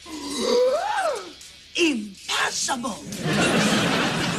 PLAY Gasp + Impossible
gasp-impossible.mp3